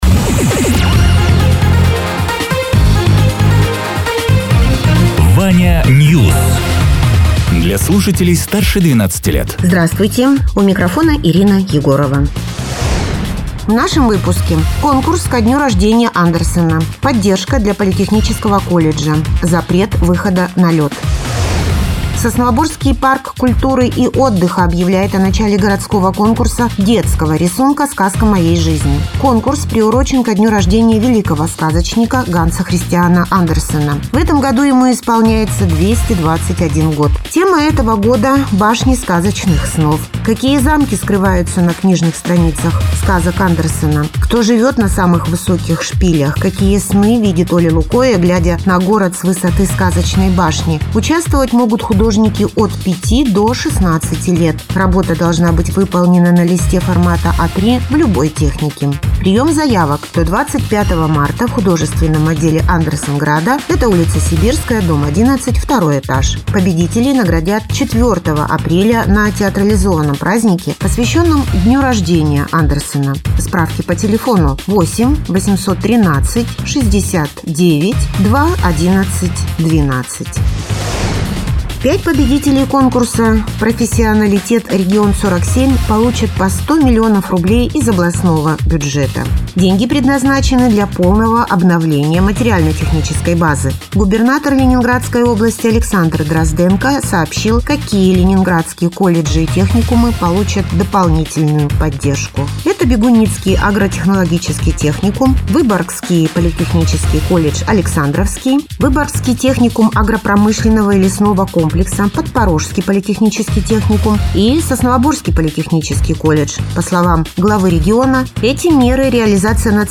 Радио ТЕРА 18.03.2026_08.00_Новости_Соснового_Бора